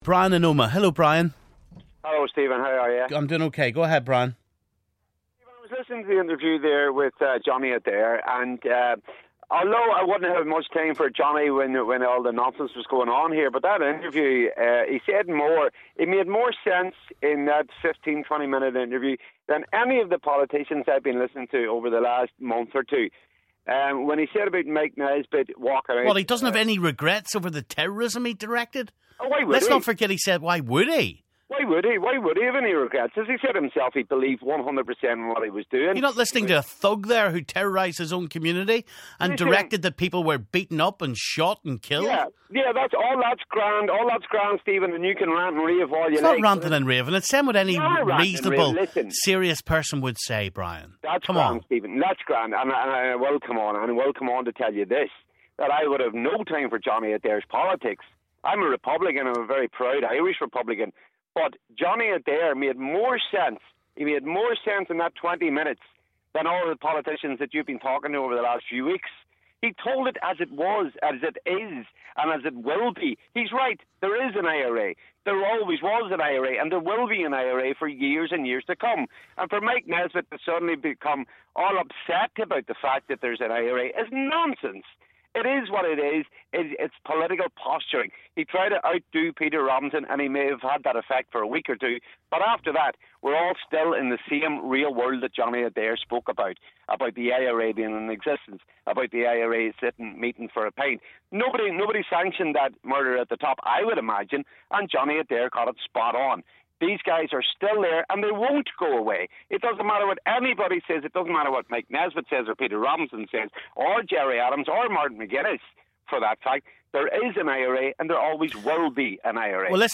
Callers react to the interview.